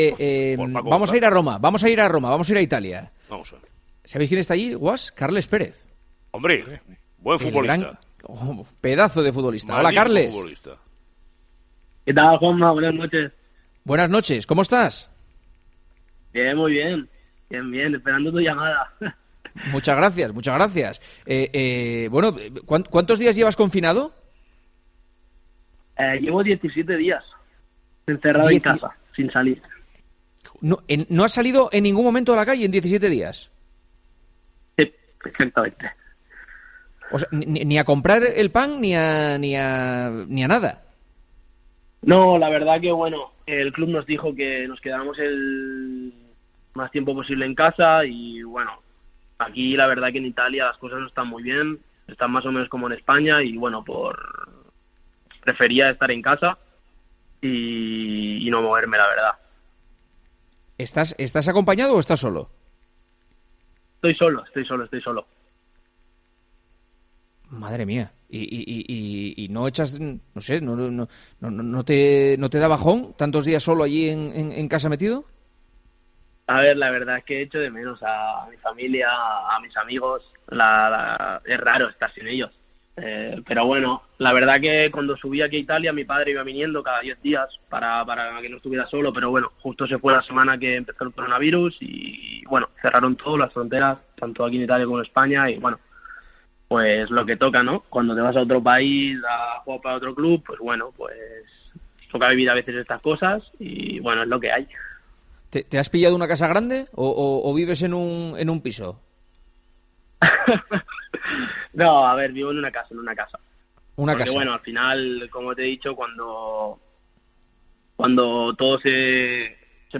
AUDIO: El jugador de la Roma, ex del Barcelona, nos cuenta cómo está viviendo la cuarentena en Italia.